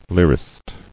[lyr·ist]